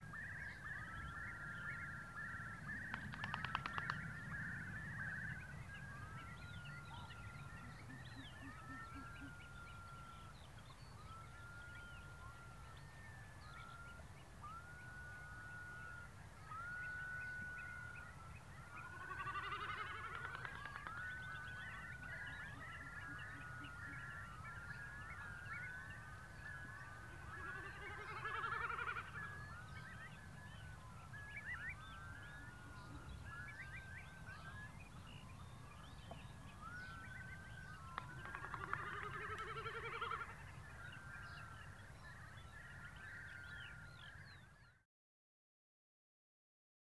CD2-78: Short-eared Owl Asio flammeus Naverdale, Orkney, Scotland, 02:53, 11 June 2010. Wingclaps and hooting of a male.
Curiously, their wing-clapping often seems to project further than their hooting.
2-78-Short-eared-Owl-Wingclaps-hooting-of-male.wav